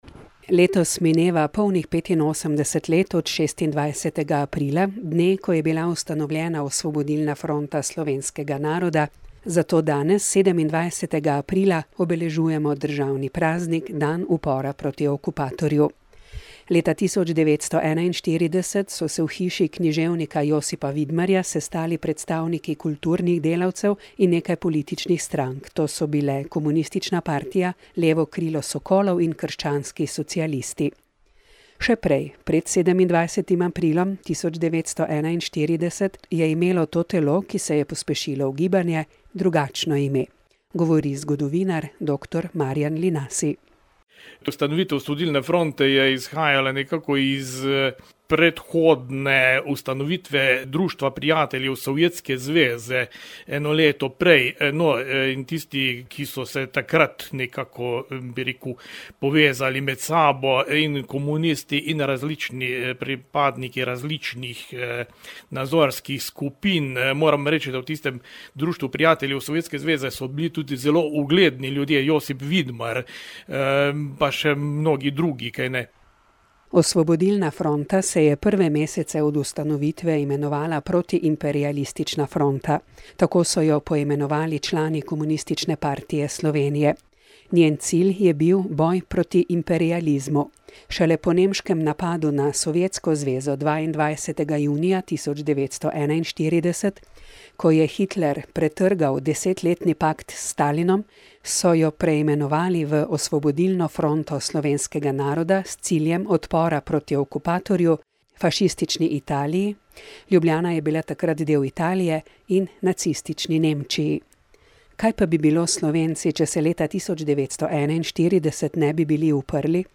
Govori zgodovinar